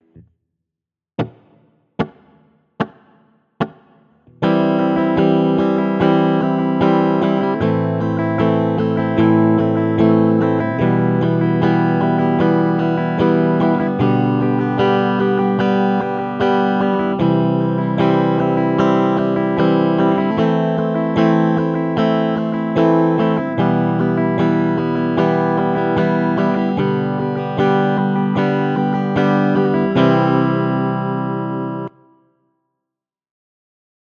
Rythme: croche - 2 doubles croches
Audio : On joue 4X BBH sur chaque accord (DO LAm MIm SOL)